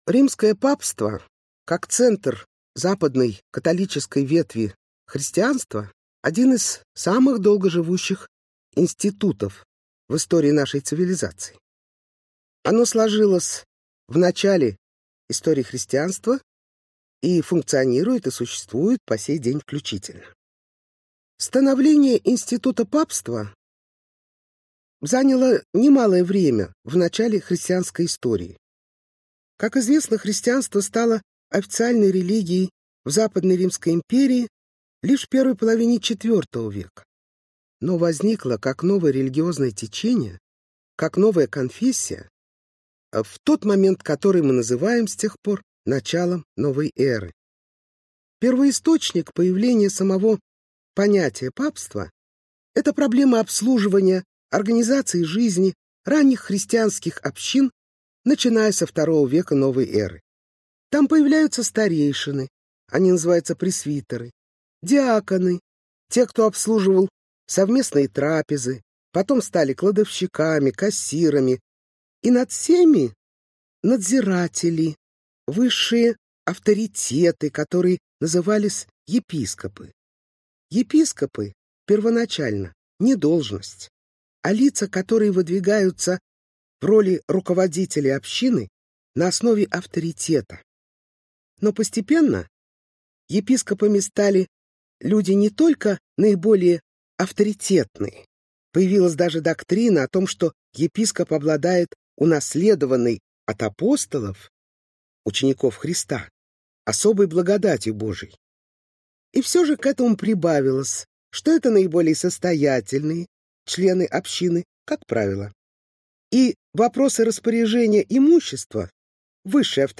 Аудиокнига Римские папы | Библиотека аудиокниг
Aудиокнига Римские папы Автор Наталия Басовская Читает аудиокнигу Наталия Басовская.